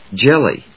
音節jel・ly 発音記号・読み方
/dʒéli(米国英語), ˈdʒɛl.i(英国英語)/